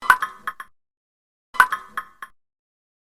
Ping Pong Electronico
UI/UX
Ping Pong Electronico is a free ui/ux sound effect available for download in MP3 format.
yt_xttJZl7MCnk_ping_pong_electronico.mp3